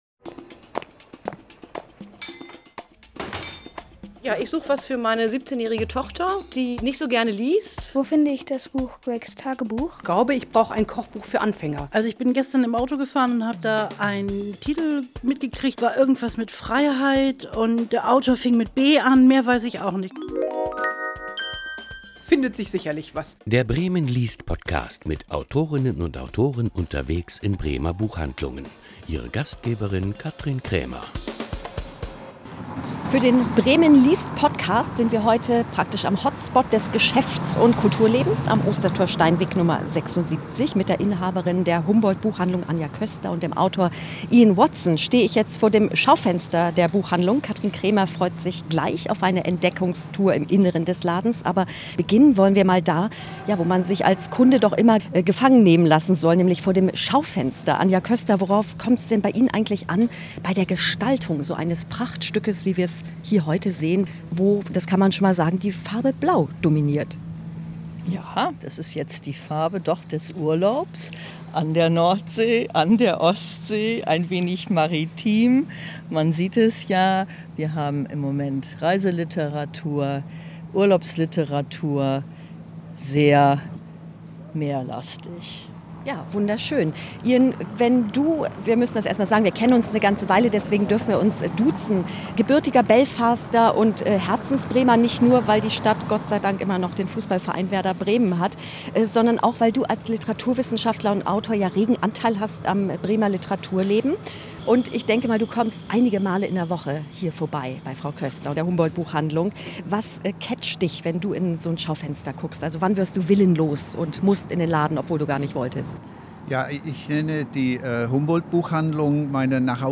Mit Autorinnen und Autoren unterwegs in Bremer Buchhandlungen